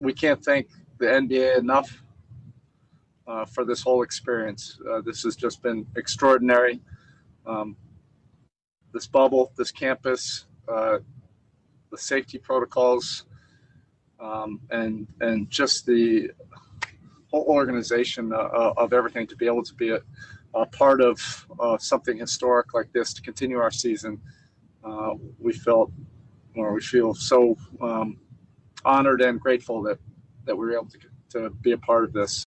Miami Heat head coach Erik Spoelstra also talked about how grateful he and the team were to be in the bubble.